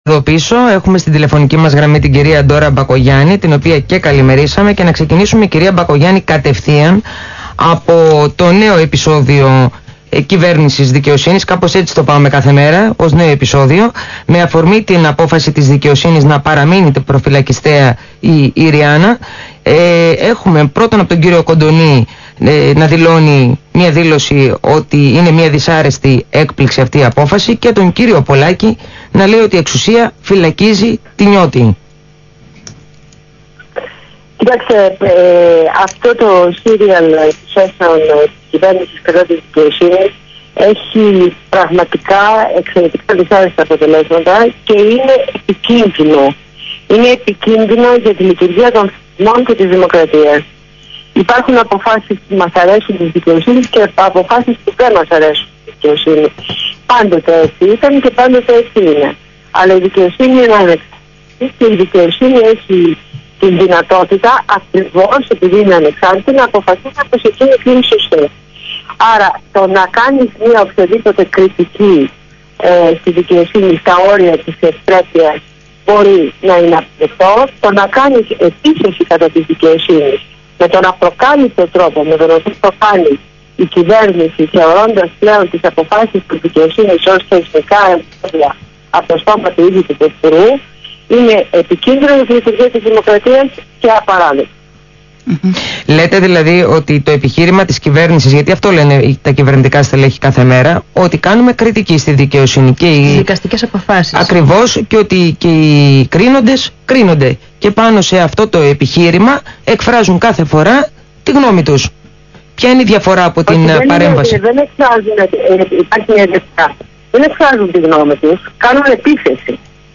Συνέντευξη στο ραδιόφωνο του ALPHA